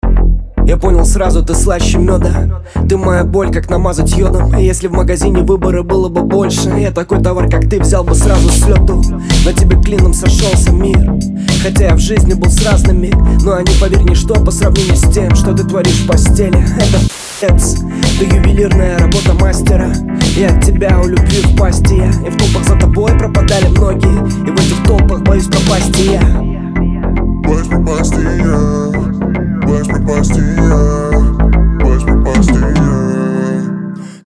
• Качество: 320, Stereo
лирика
Хип-хоп